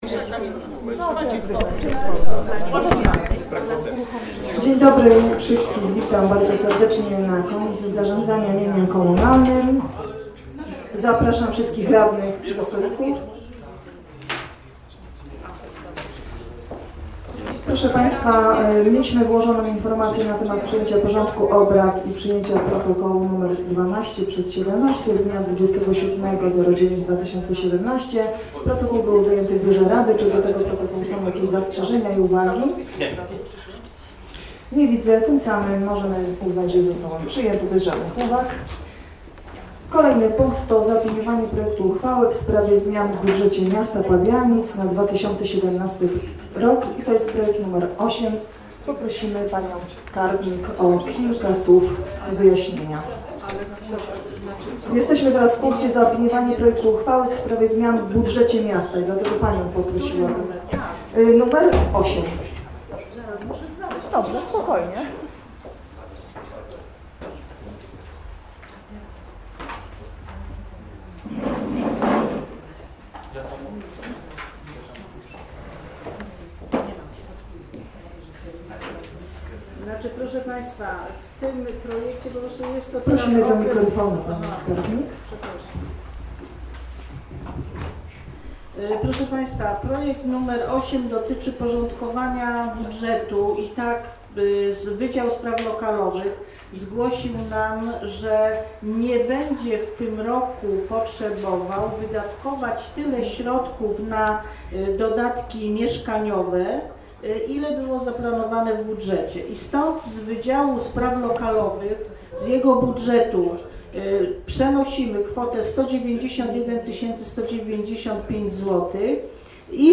Komisja Zarządzania Mieniem Komunalnym Nr 13/17 - 25 października 2017 r. - Posiedzenia - 2017 rok - Biuletyn Informacji Publicznej Urzędu Miejskiego w Pabianicach